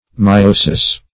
Myosis \My*o"sis\, n. [NL., fr. Gr.